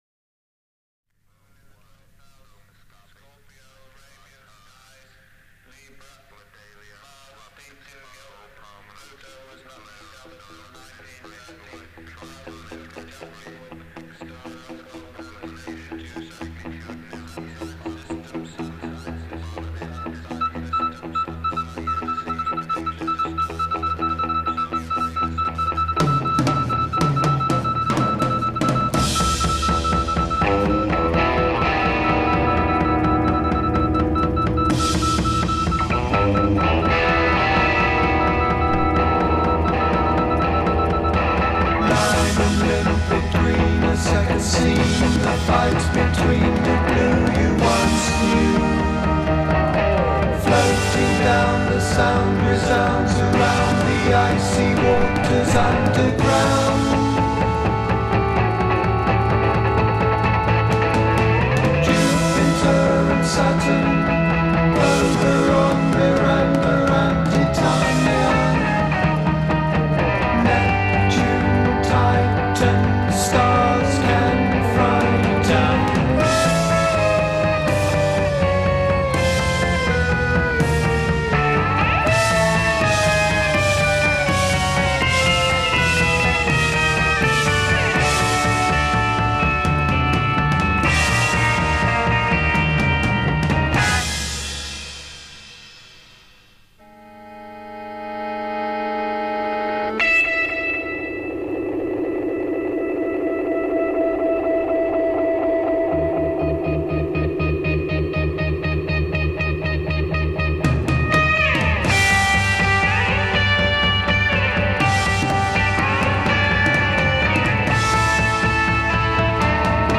bass
guitar
organ & piano
drums
Verse 3 + 4 Unison vocals over choppy guitar. a
Transition 4 + 3 Descending chromatic sequence.
Psychedelic Pop